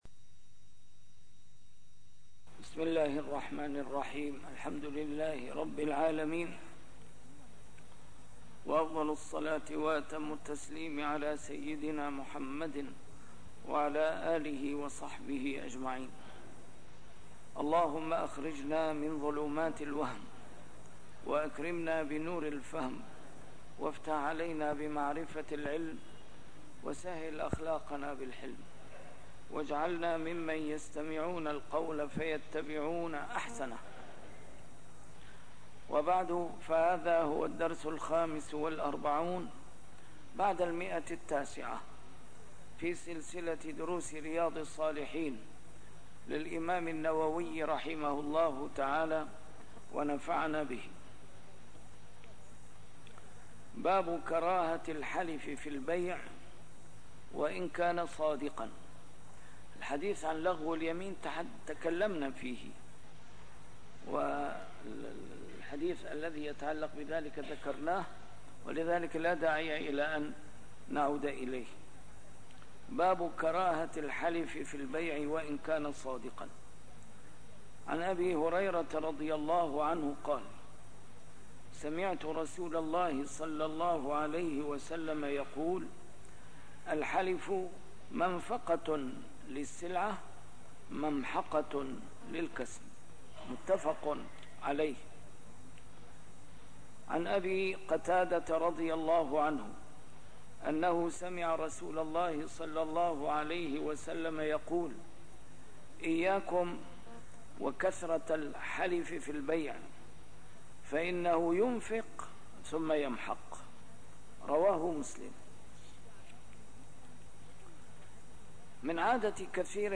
شرح كتاب رياض الصالحين - A MARTYR SCHOLAR: IMAM MUHAMMAD SAEED RAMADAN AL-BOUTI - الدروس العلمية - علوم الحديث الشريف - 945- شرح رياض الصالحين: كراهة الحلف في البيع - كراهة أن يَسأل الإنسان بوجه الله غير الجنة